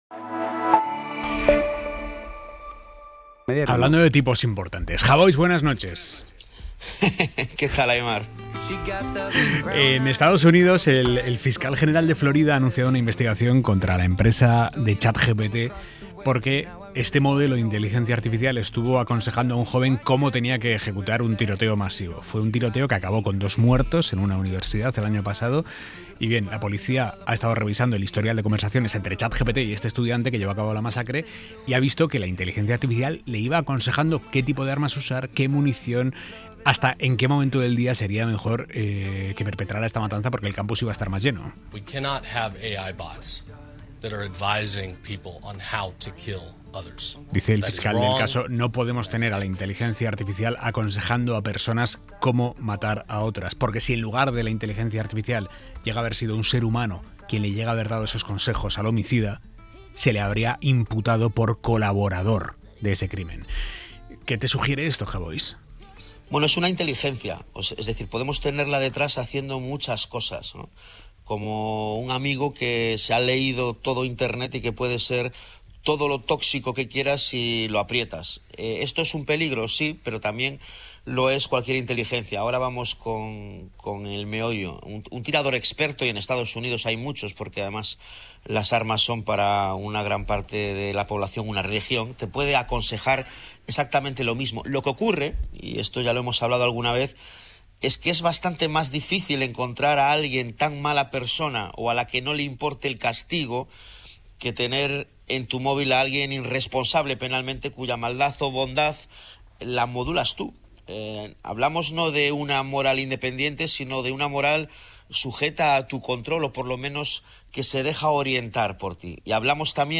El periodista y escritor reflexiona sobre la investigación criminal contra OpenAI por el rol de ChatGPT en un tiroteo en una universidad